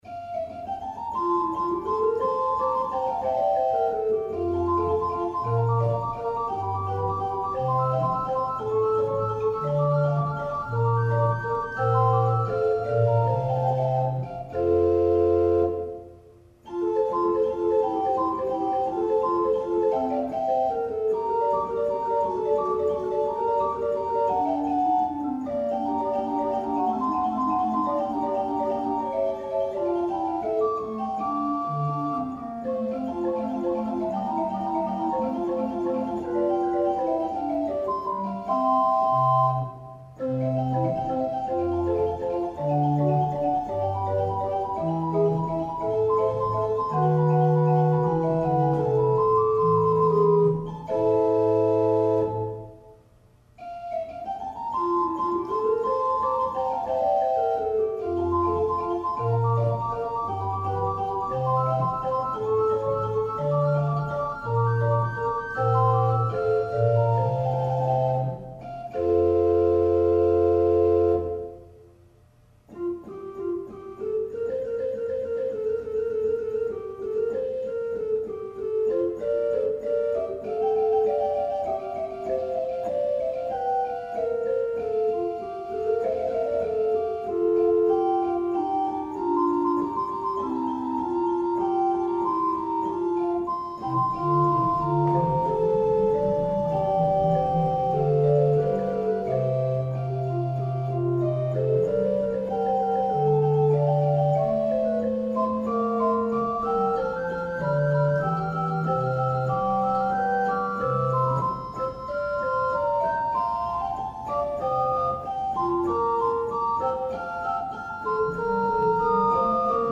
Short Baroque organ works